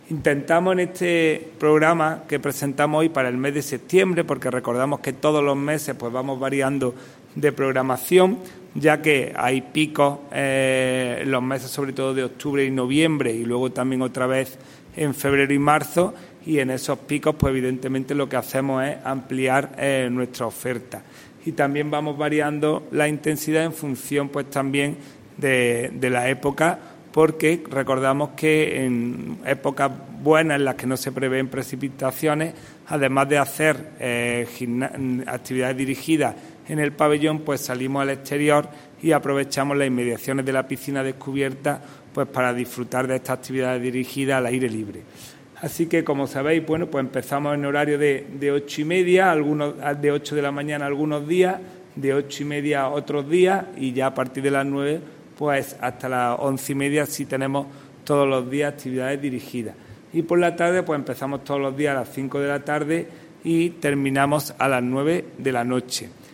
El teniente de alcalde delegado de Deportes del Ayuntamiento de Antequera, Juan Rosas, informa del reinicio de las actividades colectivas dirigidas de la nueva temporada 2022/2023 tanto en lo que respecta a las salas multidisciplinares del Pabellón Polideportivbo Fernando Argüelles como en la Piscina Cubierta Municipal.
Cortes de voz